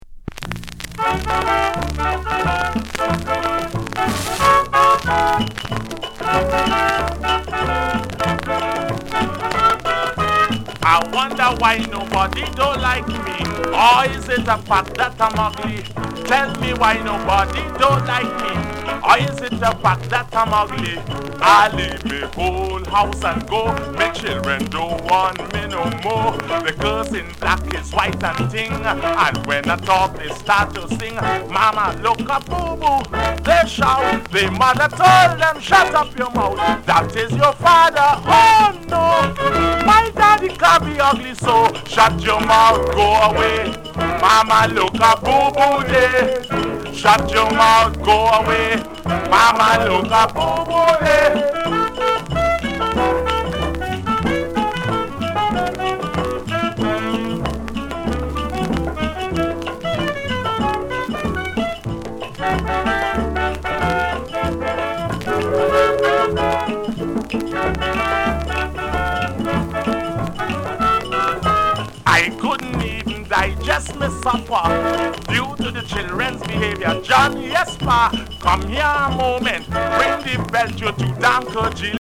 Genre: Calypso